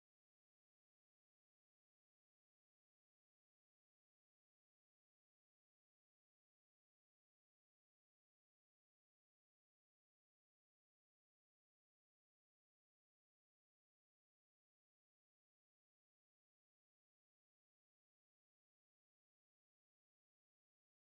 sfx-perks-prec-secondary-amb.ogg